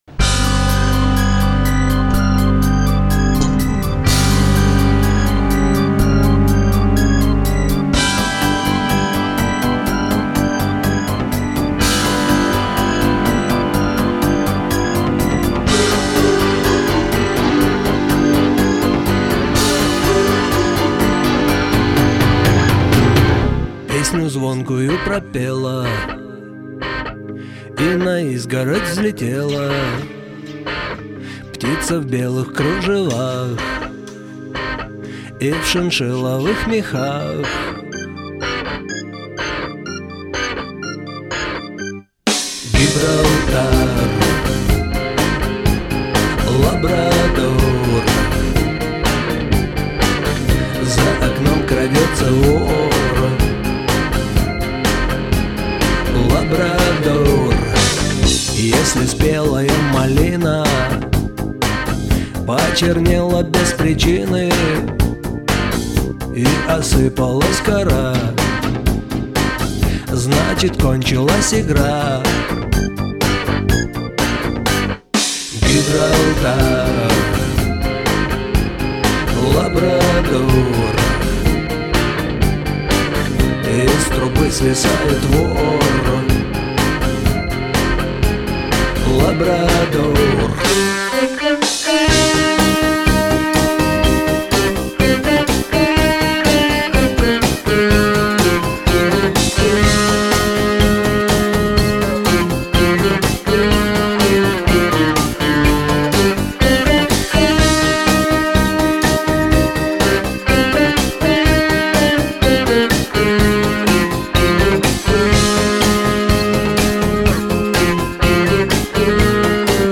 Жанр-русский рок, гитарная